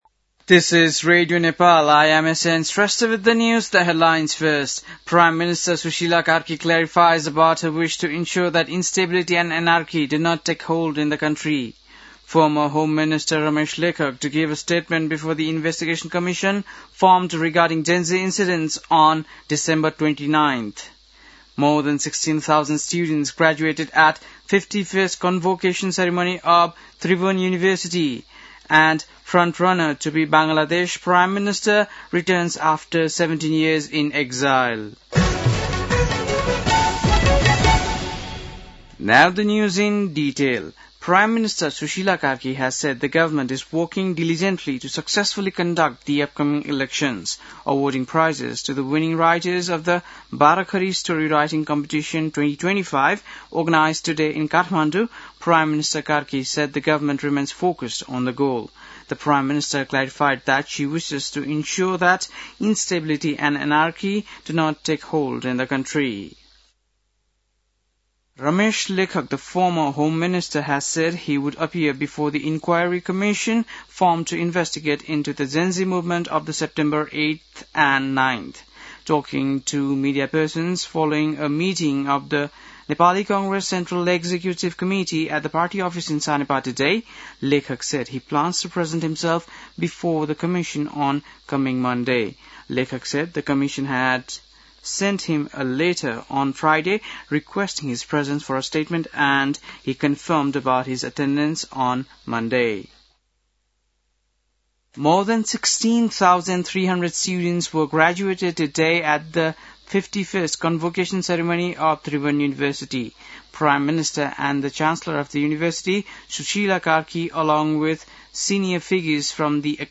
बेलुकी ८ बजेको अङ्ग्रेजी समाचार : १० पुष , २०८२
8-pm-english-news-1-1.mp3